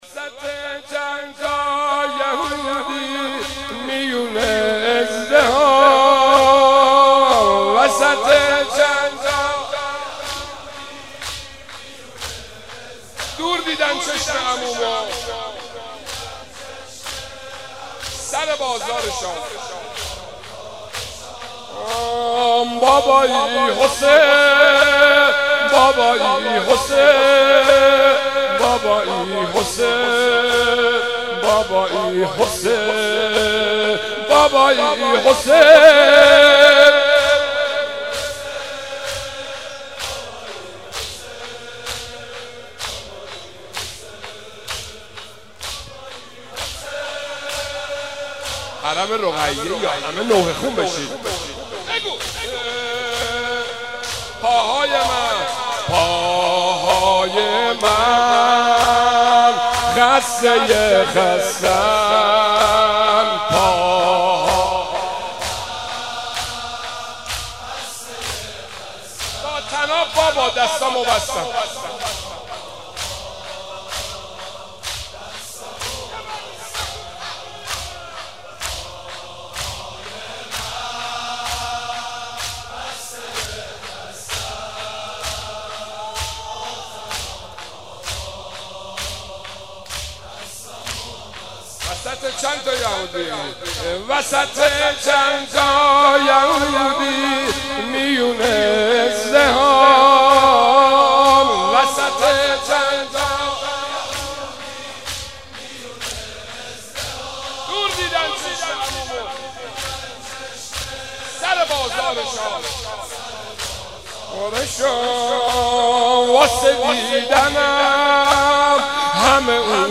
بخش اول - مناجات